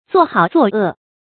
做好做恶 zuò hǎo zuò è
做好做恶发音
成语注音ㄗㄨㄛˋ ㄏㄠˇ ㄗㄨㄛˋ ㄜˋ